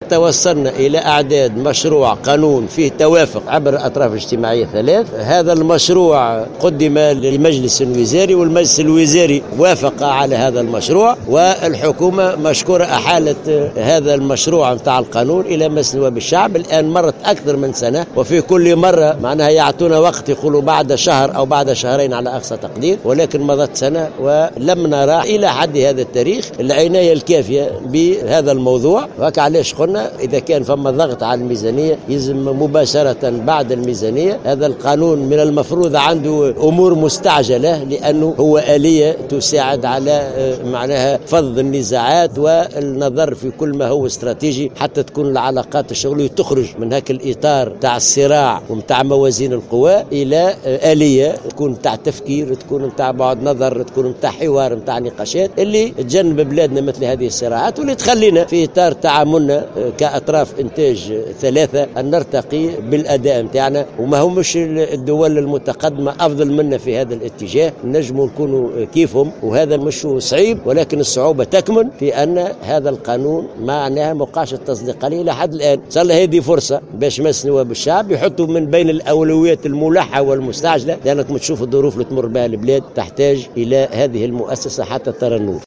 وعبر العباسي في الجلسة الافتتاحية للندوة الثلاثية لاعتماد خطة عمل لتنفيذ العقد الاجتماعي، المنعقدة بضاحية قمرت تحت اشراف رئيس الحكومة يوسف الشاهد وبالتعاون مع منظمة العمل الدولية، عن تطلع المنظمة الشغيلة إلى أن يكون مشروع قانون إحداث المجلس الوطني للحوار الاجتماعي أوّل قانون يعرض على أنظار مجلس نوّاب الشعب بعد المصادقة على قانون المالية.